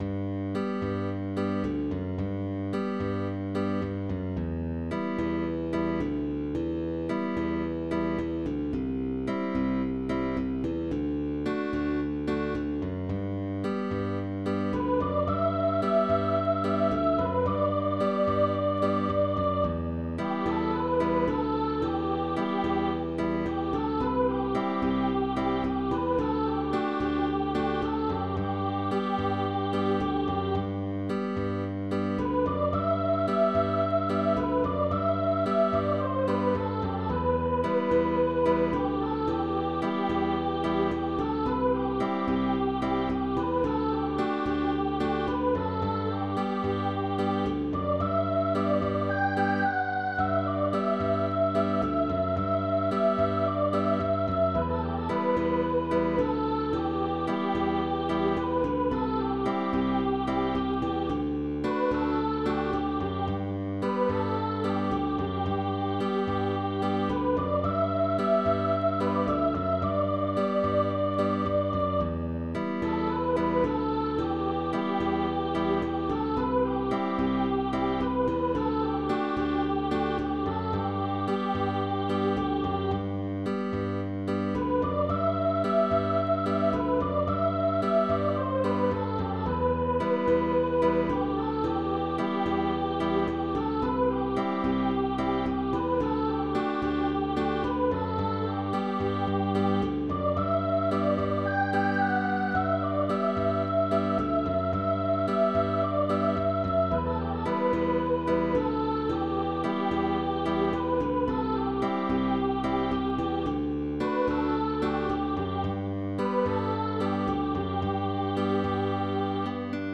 With optional bass.
Tag: Pop Songs